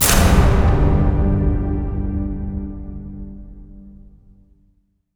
LC IMP SLAM 6B.WAV